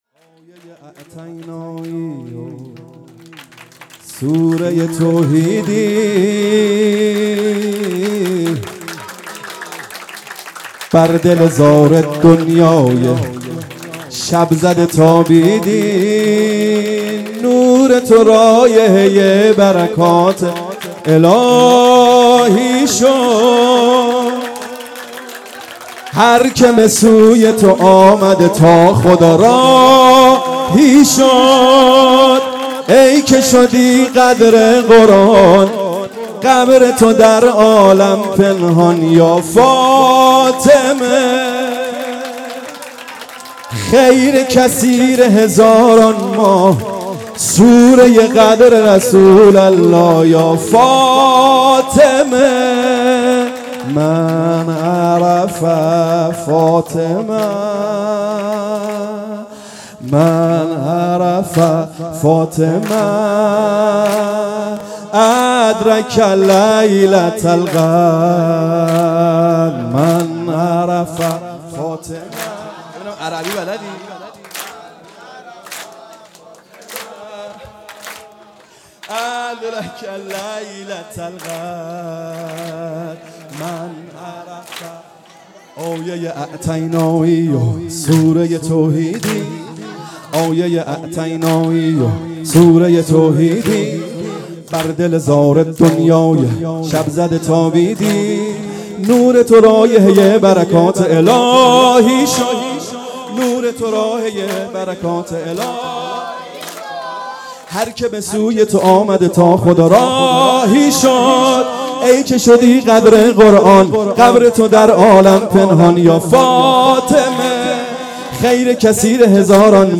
سرود - آیه ی اعطینایی و سوره توحیدی
جشن ولادت حضرت زهرا سلام الله علیها